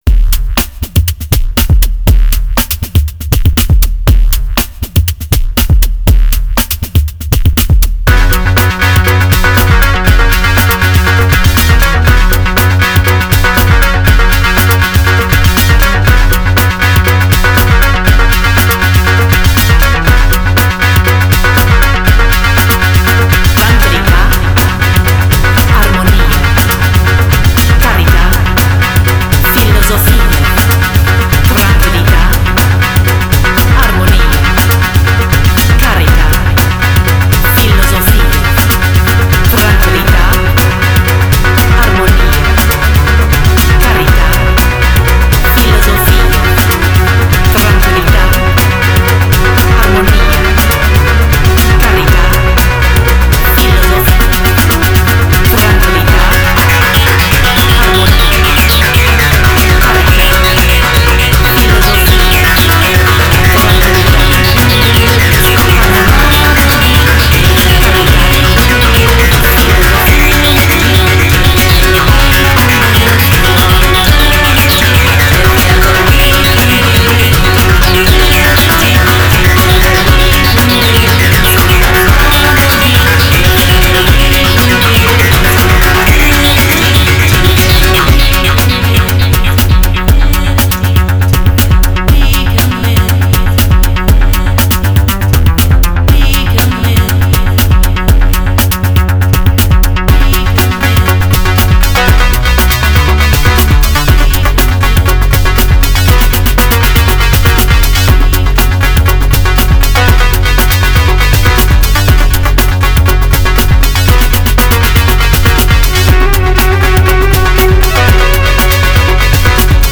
solo dance CD